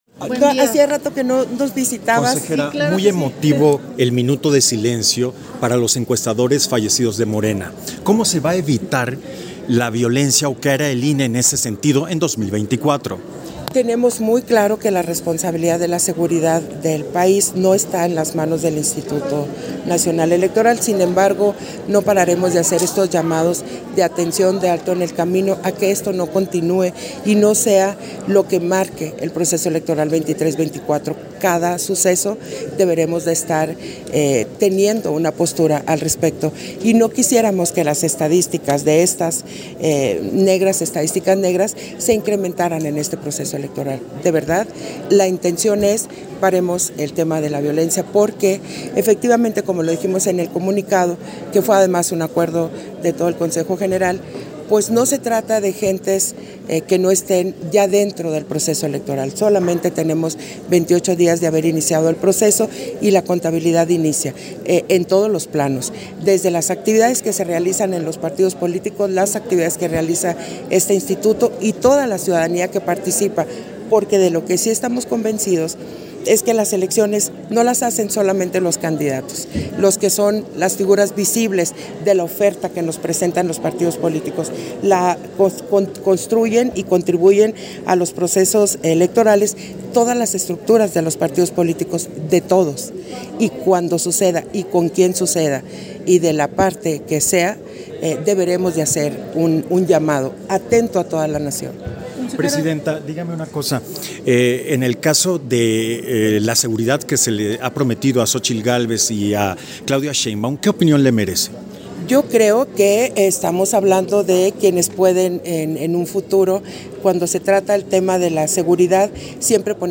Versión estenográfica de la entrevista que concedió Guadalupe Taddei, al termino de la Sesión Extraordinaria del Consejo General